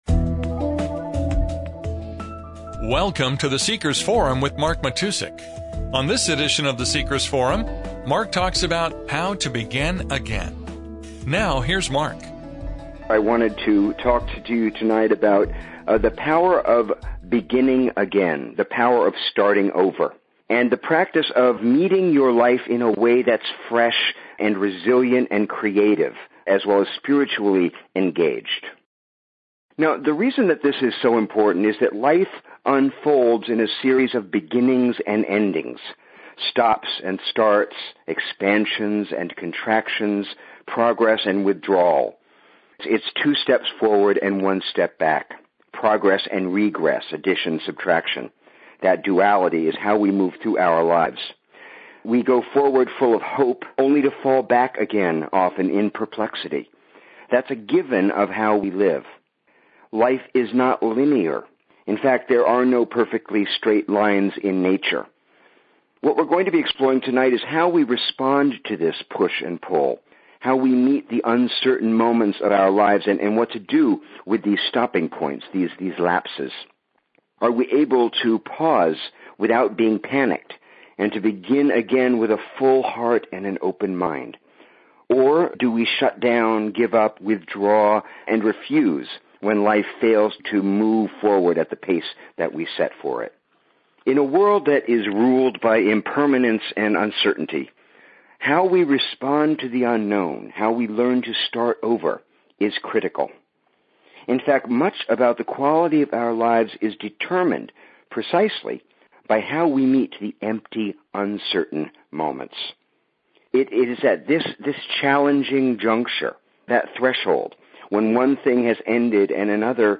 The Seekers Session: Teleconference with Q & A